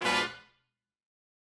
GS_HornStab-Fdim.wav